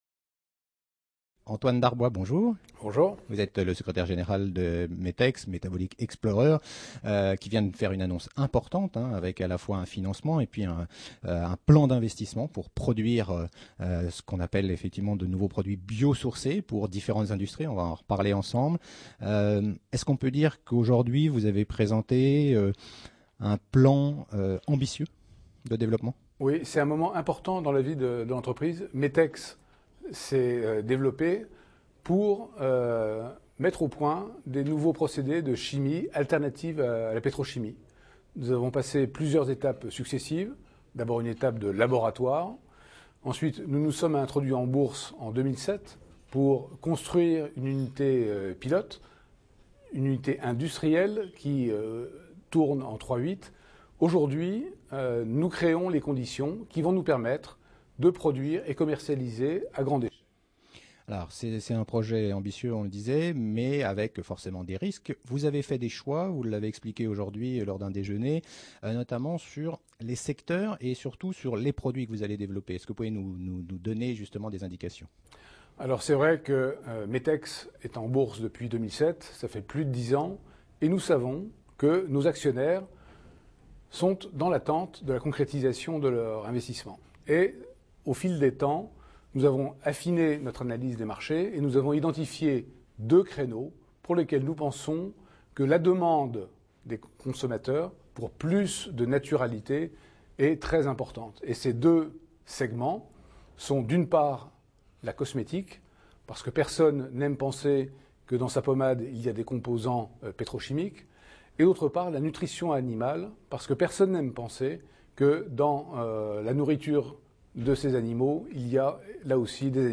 Category: L'INTERVIEW